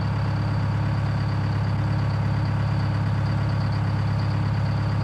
BoatEngine.ogg